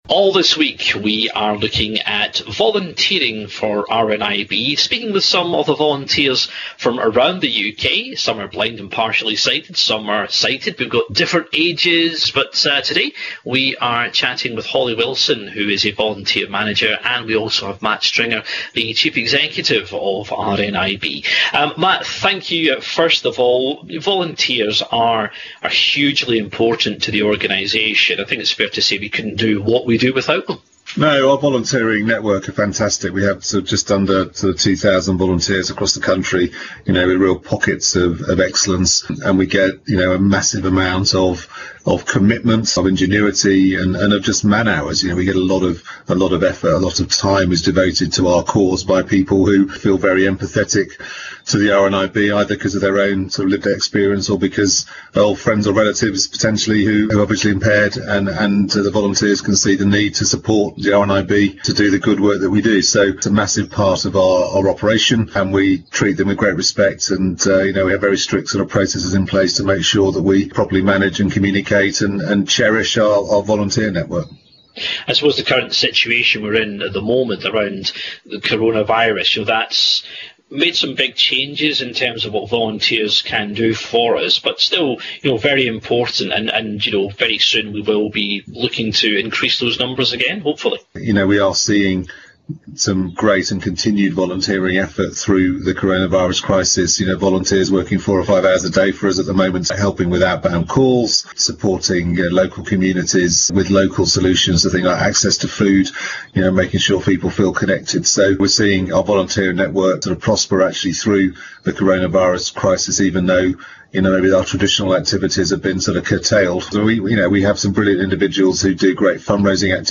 We are going to hear from some of the volunteers who give up their time forRNIB, what they do, why they do it and why they would recommend supporting the UK sight loss charity.